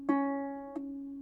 Harpe.wav